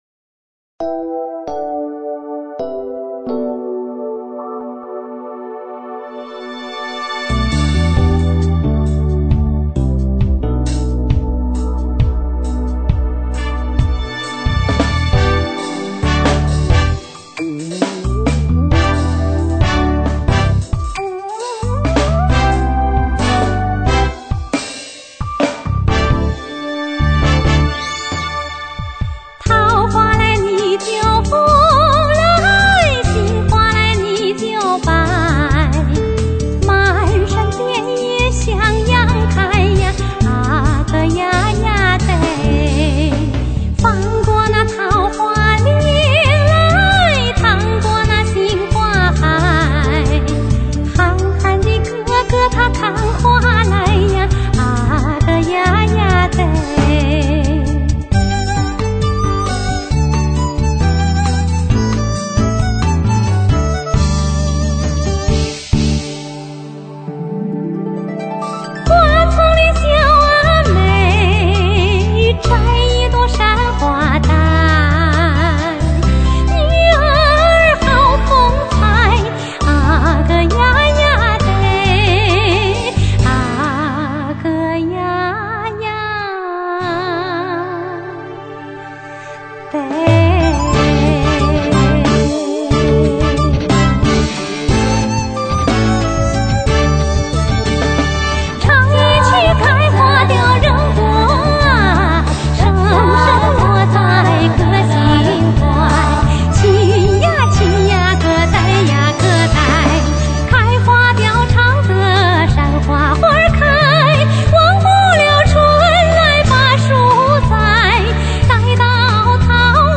采用电声乐队伴奏 配乐中加入爵士和摇滚等流行元素，赋予传统民歌很强的时尚性和时代性
根据山西传统民歌改编